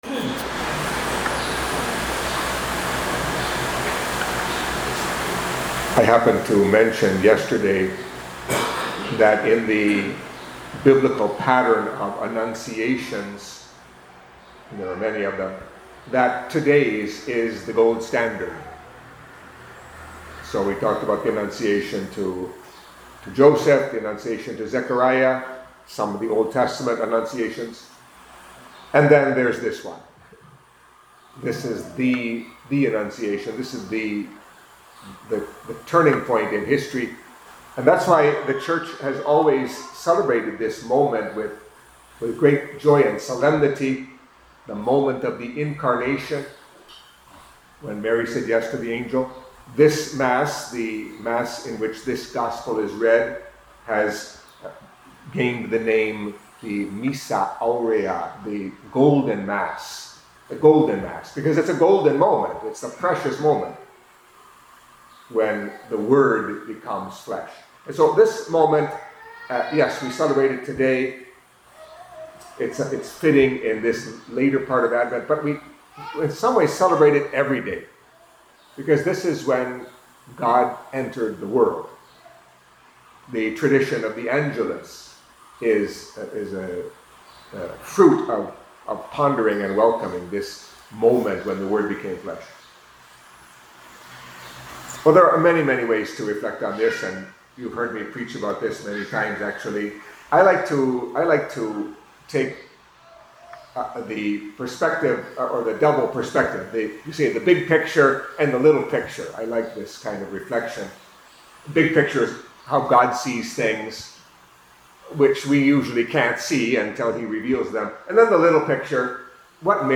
Catholic Mass homily for Friday of the Third Week of Advent